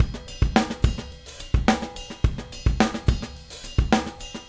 Retro Funkish Beat 02.wav